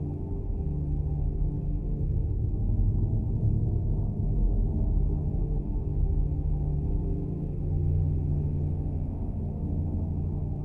hum7.wav